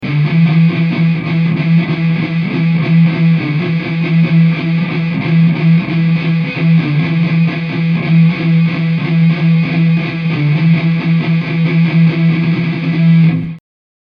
For example, listen to the following riff (made of only two single notes):
Although this riff only uses two notes (“D” and “E”), it utilizes rhythm in an interesting manner in order to maintain the attention of the listener. In this case, the rhythm of the guitar riff uses “syncopation” (playing between the beats) to create tension by emphasizing the space between the strong beats of the measure.
In this case, the beat is in common 4/4 time (4 beats per measure) and the guitar riff does not always play directly on any of the 4 beats.
singlelineriff.mp3